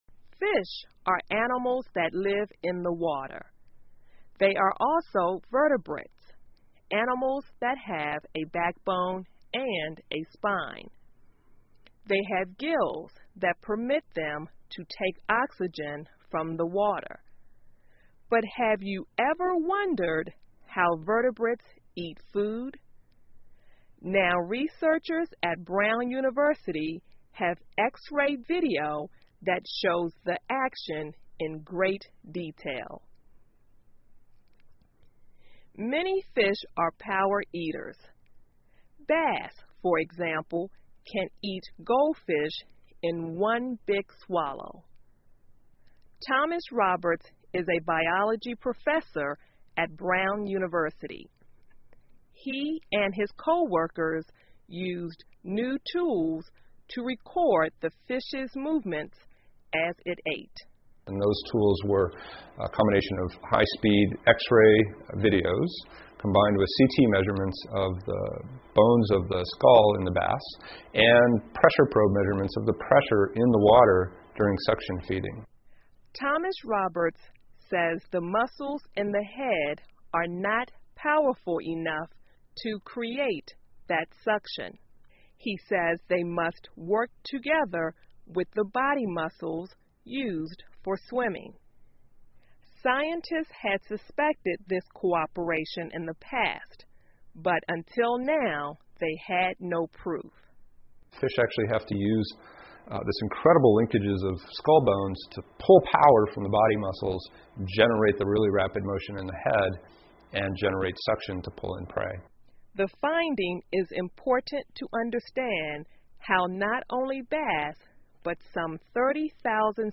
VOA慢速英语2015 Fish Use Whole Bodies When They Eat 听力文件下载—在线英语听力室